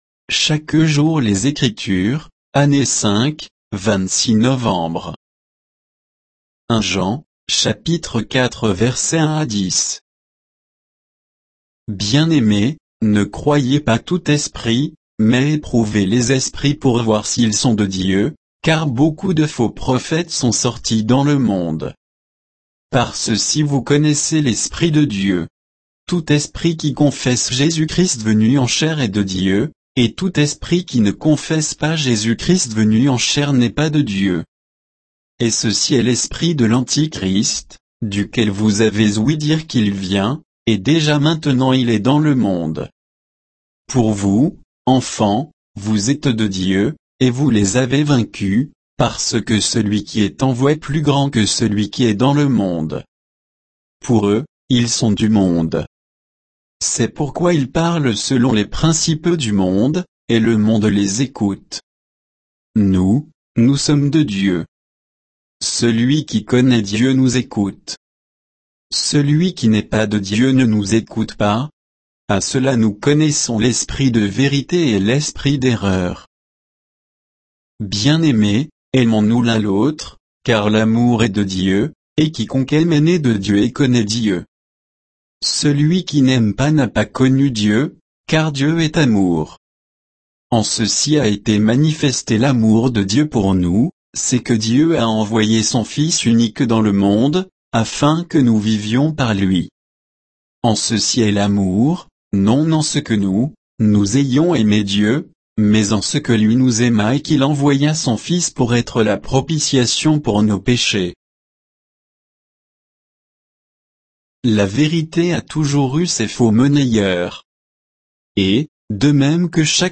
Méditation quoditienne de Chaque jour les Écritures sur 1 Jean 4